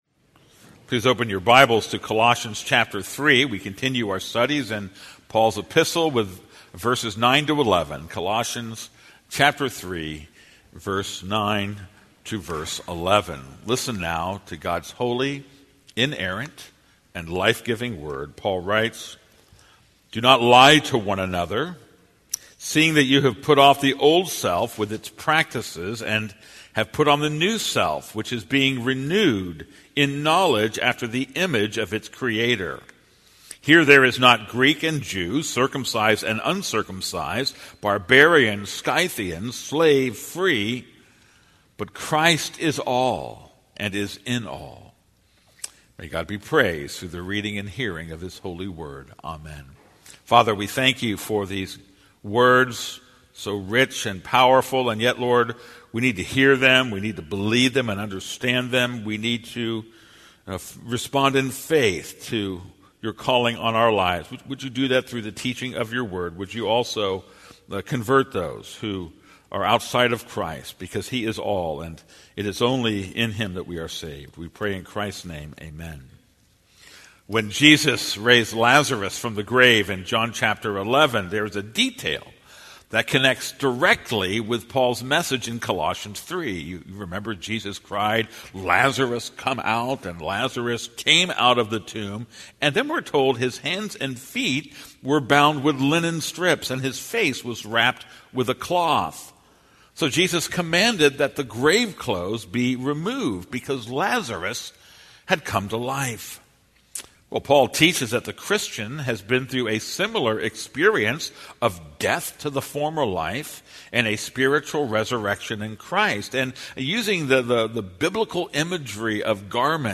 This is a sermon on Colossians 3:9-11.